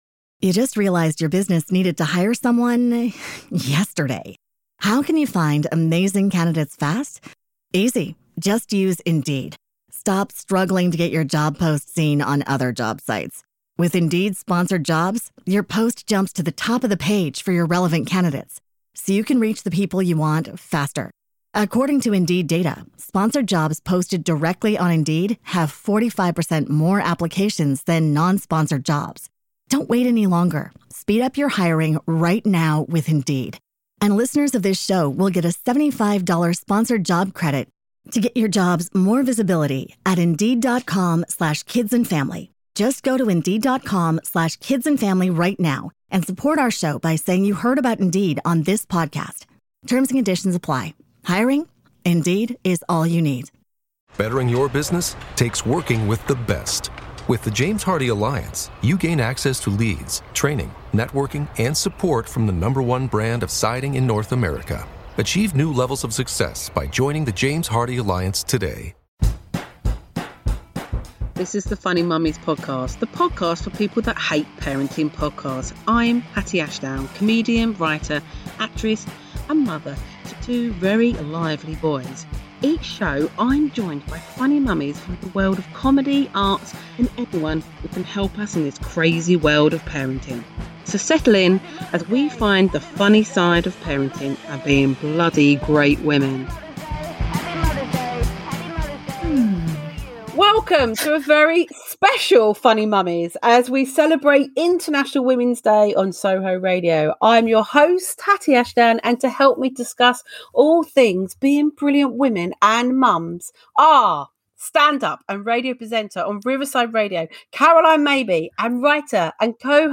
This show was recorded via Zoom